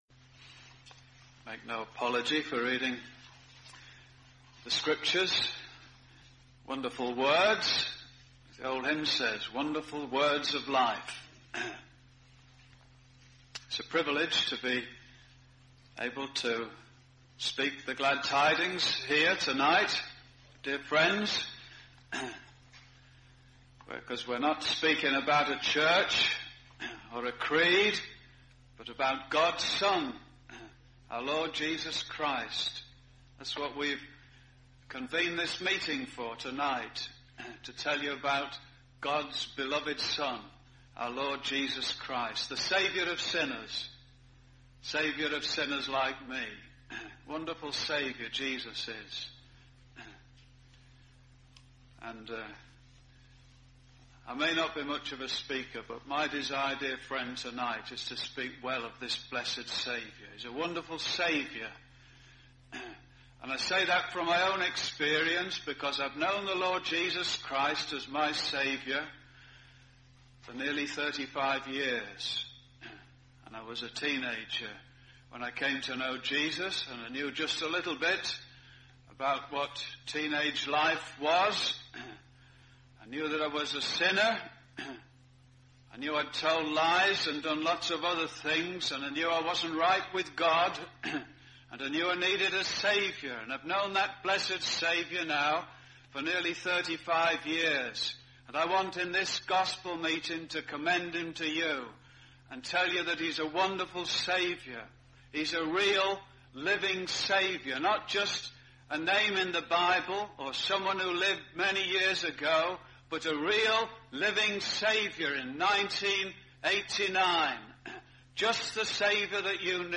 Gospel Preachings
(The Bible scriptures are not read in this recording but we have listed them below.)